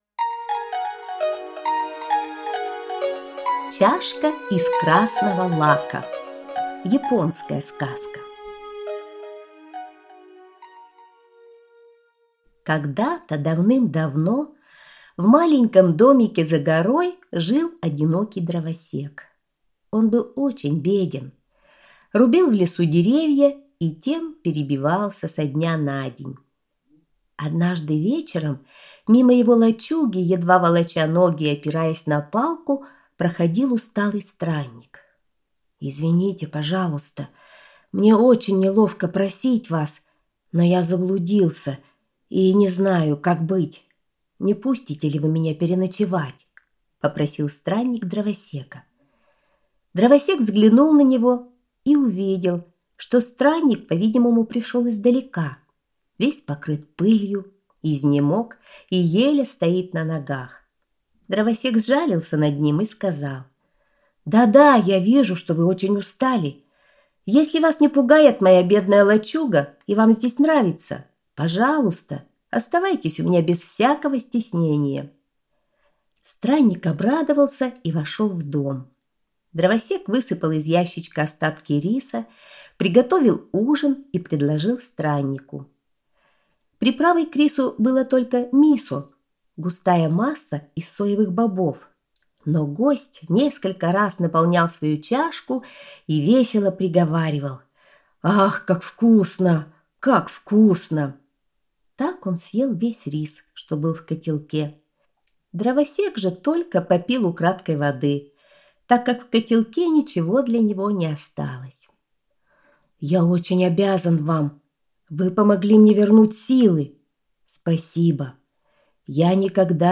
Аудиосказка «Чашка из красного лака»